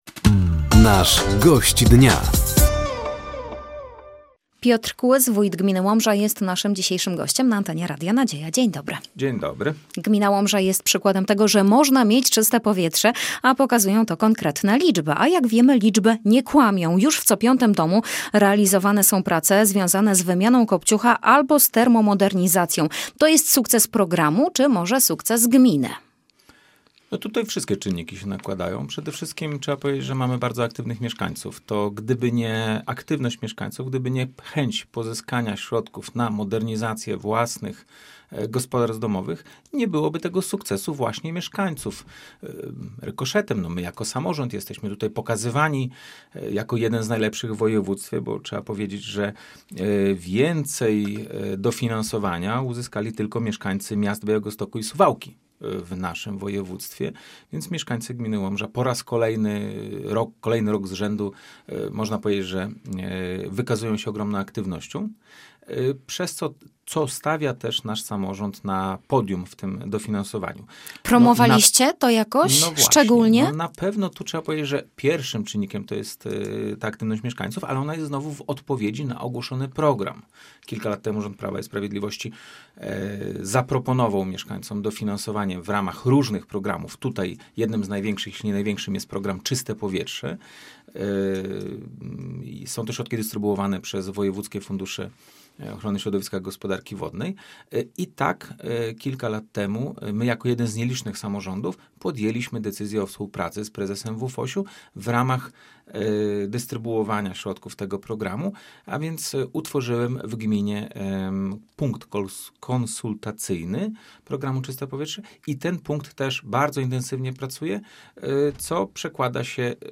Gościem Dnia Radia Nadzieja był wójt gminy Łomża Piotr Kłys. Tematem rozmowy była szansa na nowe miejsca pracy w regionie, konieczność rozwoju infrastruktury drogowej oraz zbliżający się Festyn Kultury Średniowiecznej na św. Wawrzyńca.